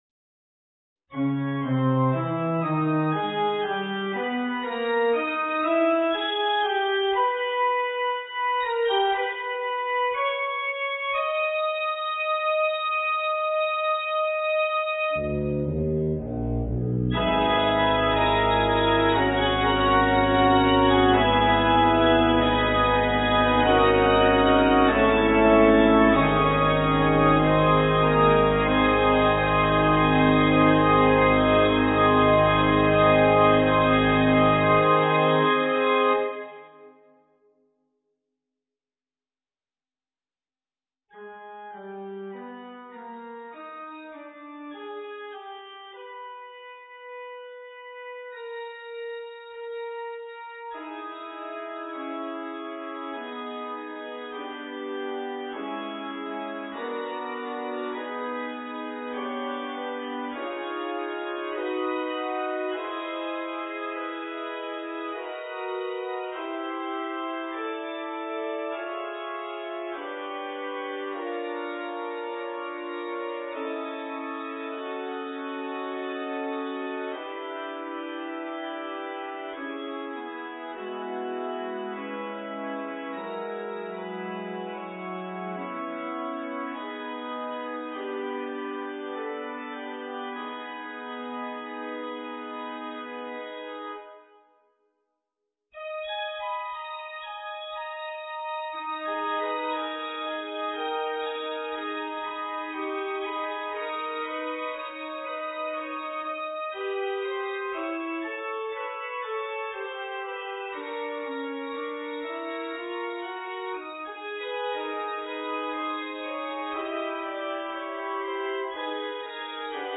for organ